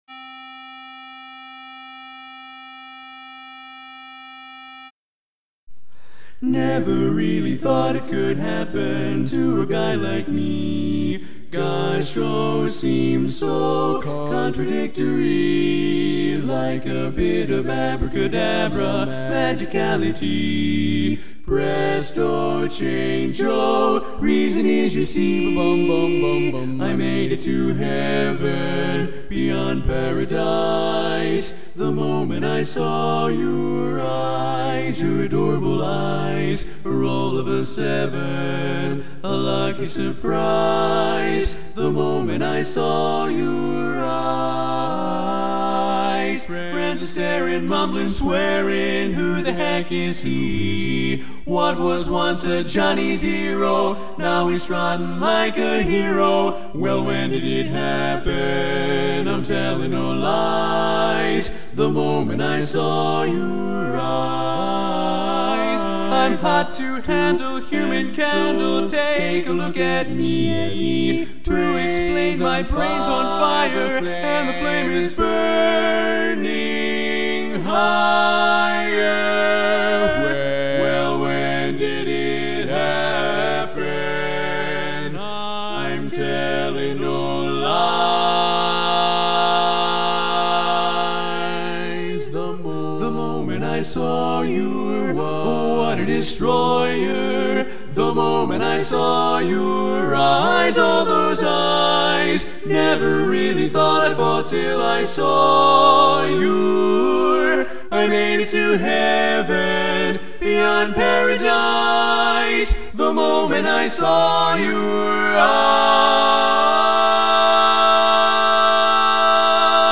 Kanawha Kordsmen (chorus)
Up-tempo
C Major
Full Mix